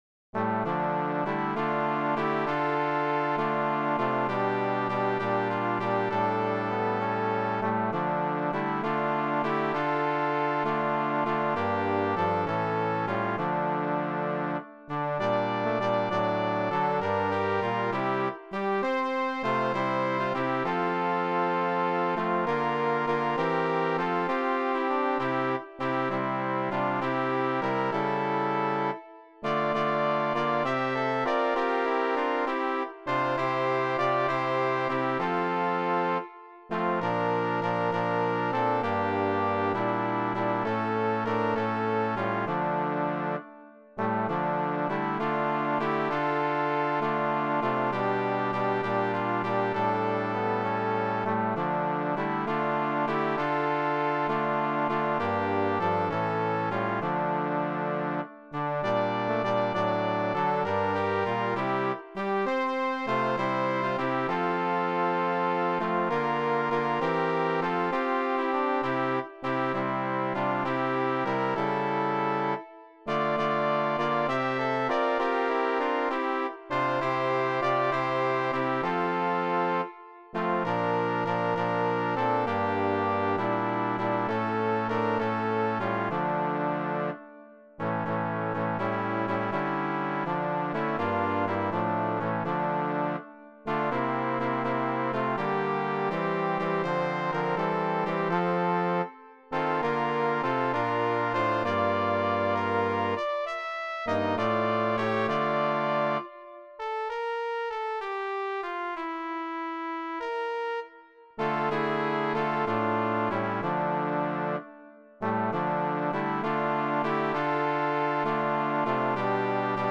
Cor mixt